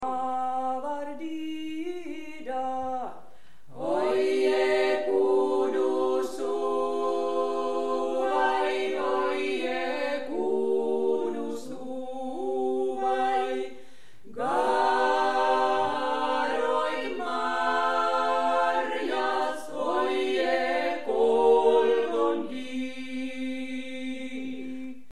Garoit Marjaz (Kuhmon Kalevalakylä 2004). Rekryyttilaulu Mordvanmaalta.